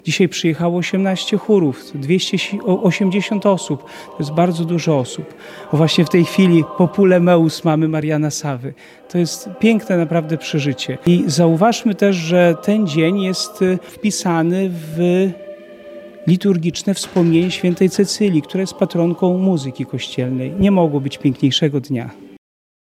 280 osób z 18 chórów z całej diecezji zaprezentowało się w sobotę (22.11) w łomżyńskiej Katedrze podczas Jubileuszowego Przeglądu Chórów Diecezji Łomżyńskiej.